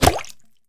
bullet_impact_water_05.wav